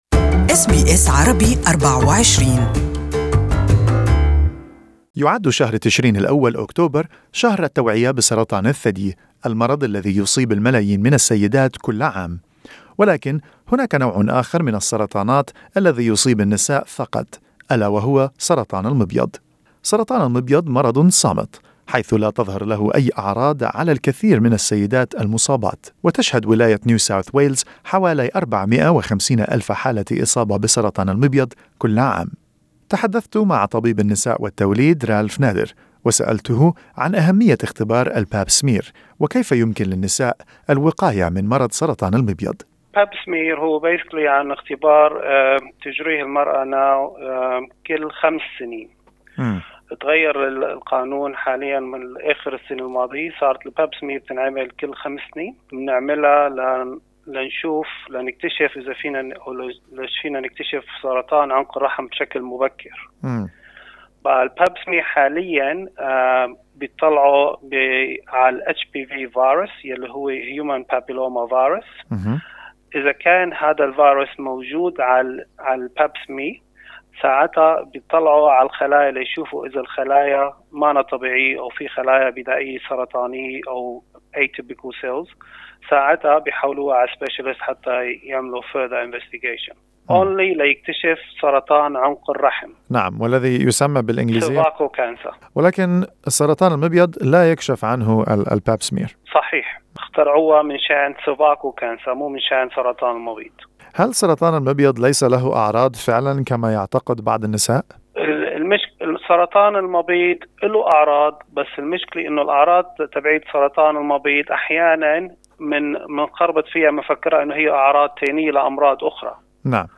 تحدثنا مع طبيب النساء و التوليد